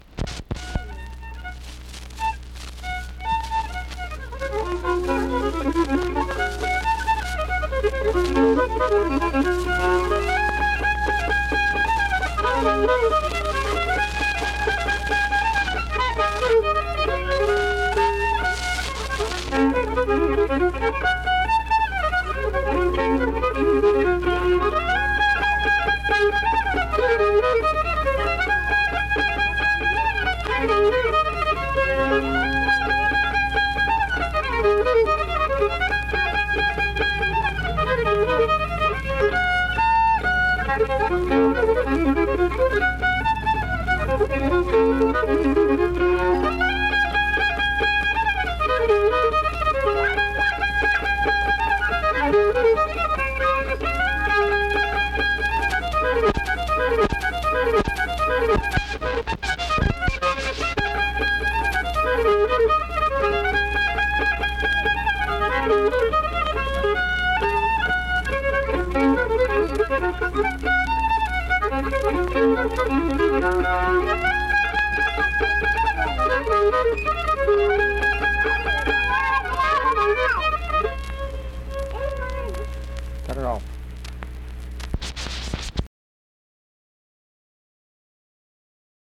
Instrumental fiddle performance.
Instrumental Music
Fiddle
Wood County (W. Va.), Vienna (W. Va.)